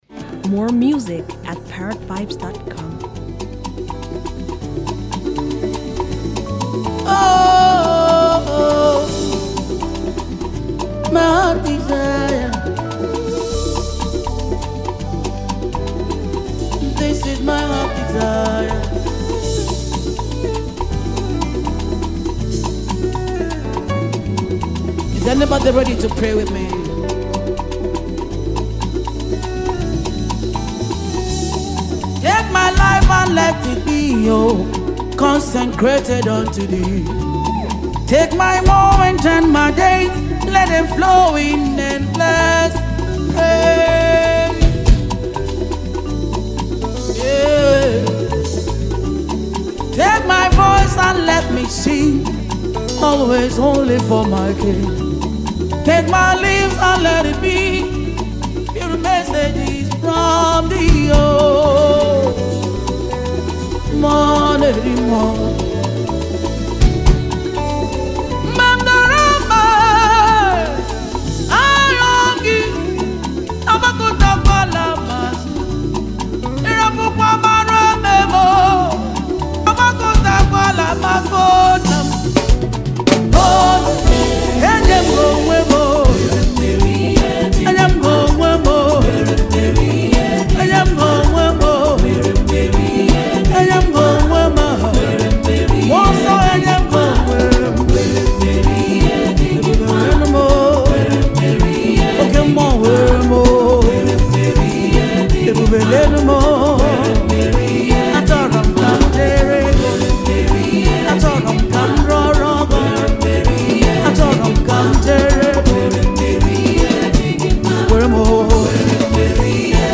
is a powerful gospel ministration